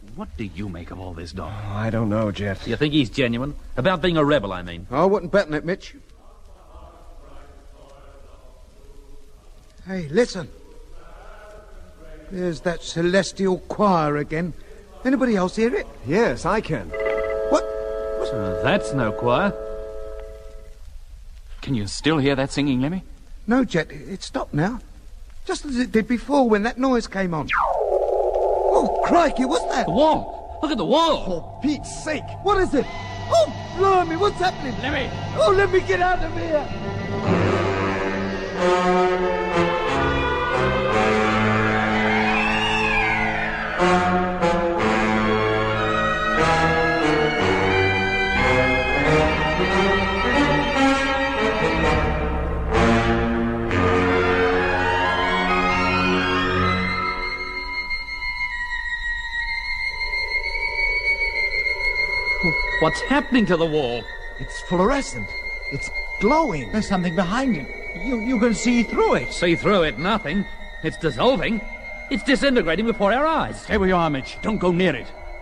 In de Britse versie probeert men de spanning die dat veroorzaakt te versterken met muziek, in tegenstelling tot de Nederlandse versie waar het fragment gewoon doorloopt.